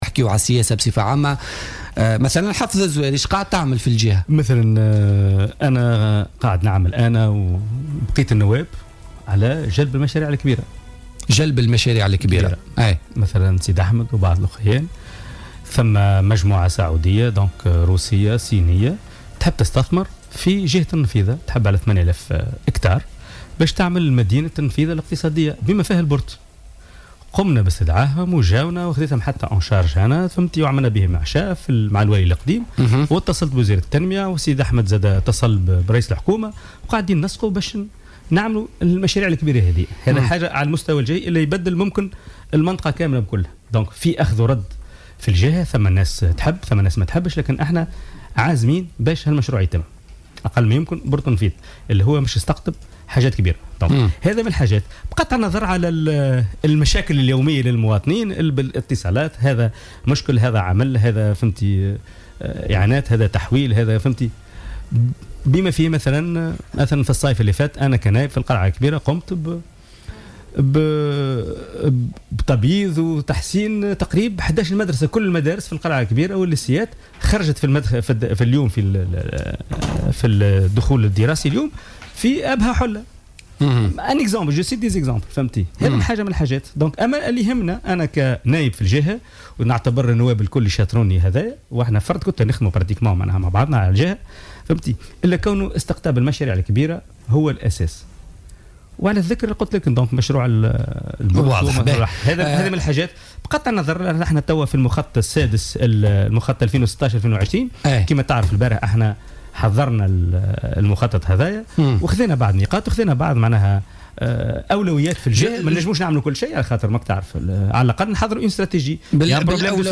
كشف حافظ الزواري،النائب عن حزب آفاق تونس ضيف "بوليتيكا" اليوم الثلاثاء عن رغبة مجموعة سعودية روسية صينية للاستثمار في جهة النفيضة و إنشاء مشروع "مدينة النفيضة الاقتصادية" على مساحة 8 هكتارات.